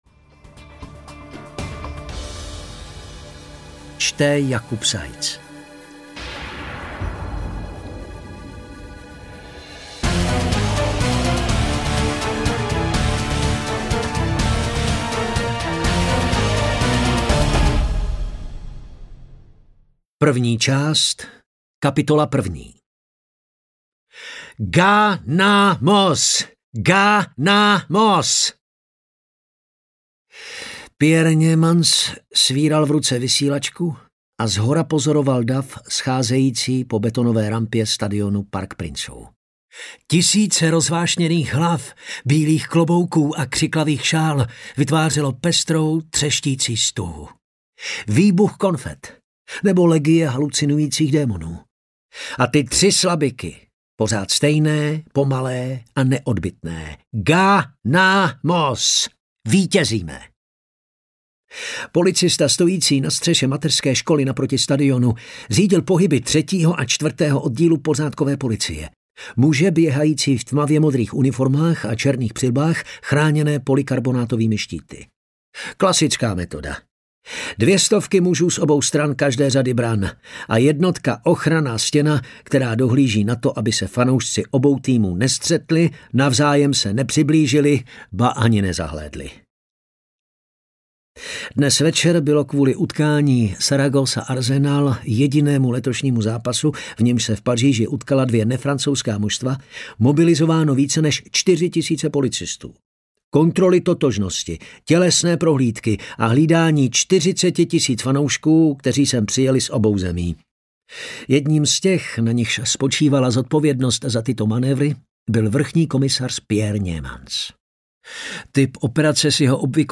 Purpurové řeky audiokniha
Ukázka z knihy